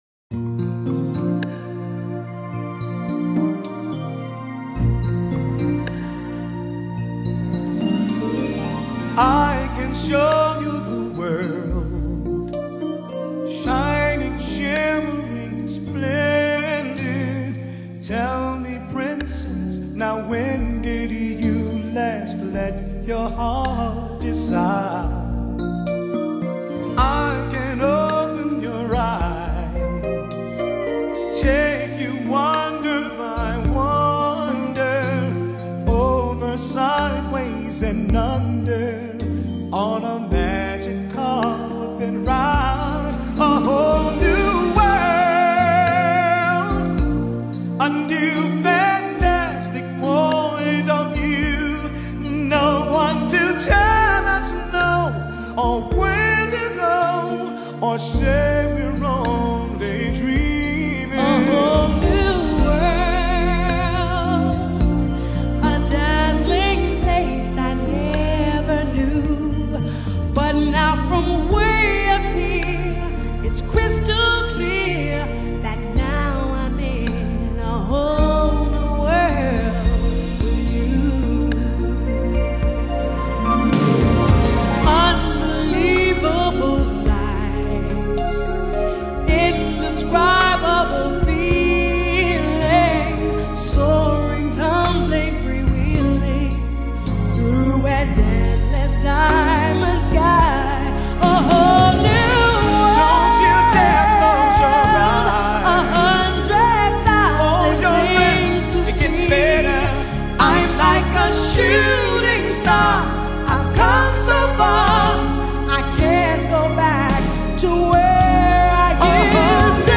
R&B, Jazz, Vocal (320K/mp3)